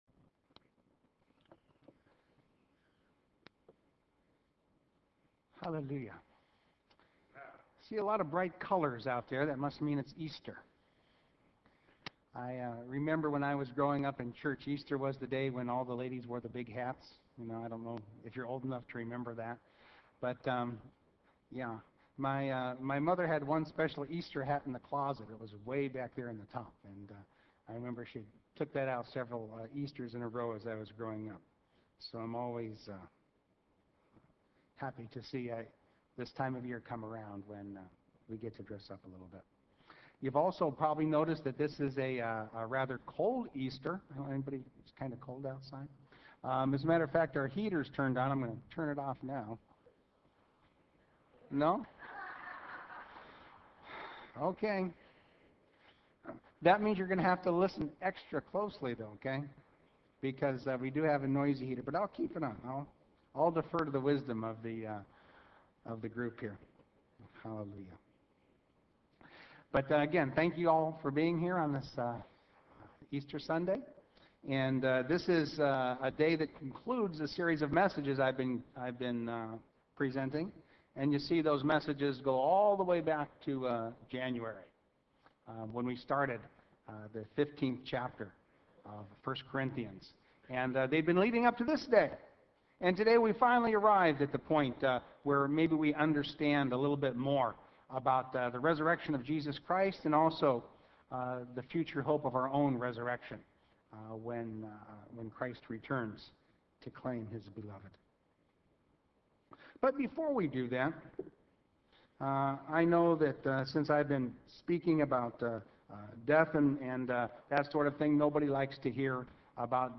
Six Sermons